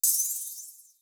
Southside Open Hatz (11).wav